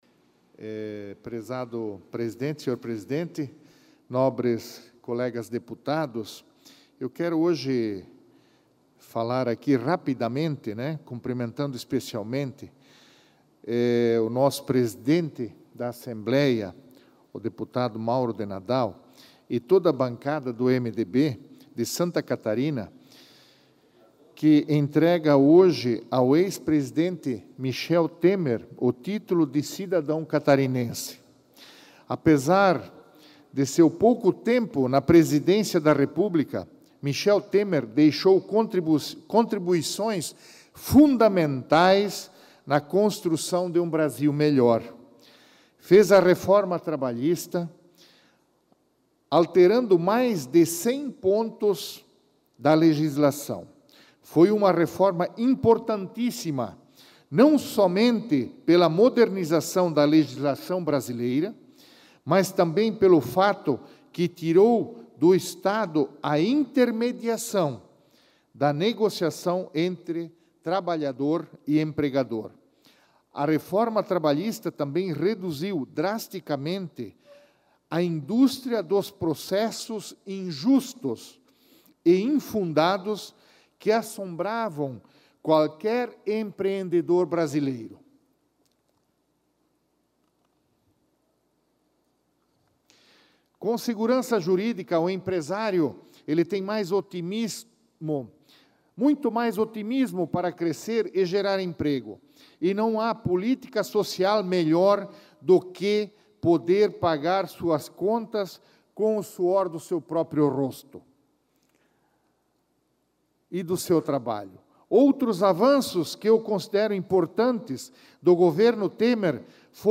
Pronunciamento da sessão ordinária desta quinta-feira (17)
Confira o pronunciamento do deputado na sessão ordinária desta quinta-feira (17):